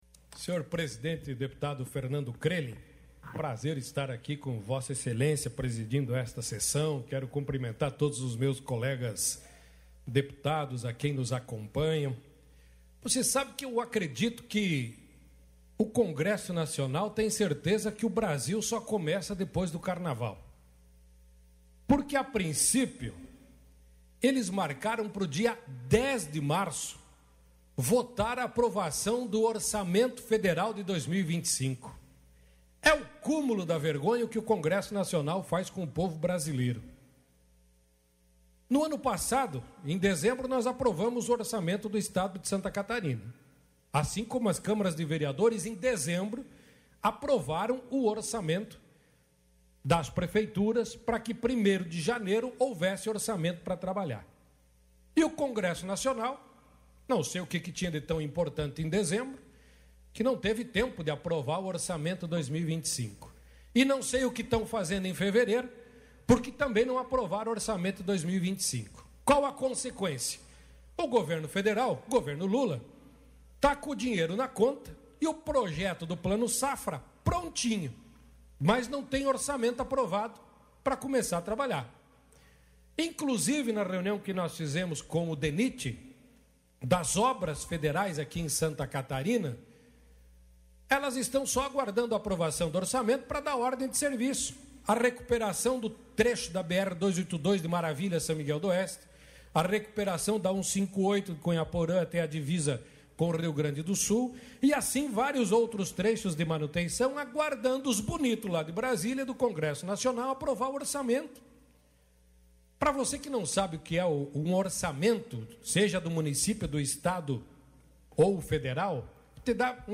Pronunciamentos da sessão ordinária desta quarta-feira (26)
- deputado Fabiano da Luz (PT);
Dep._Fabiano_Da_Luz.mp3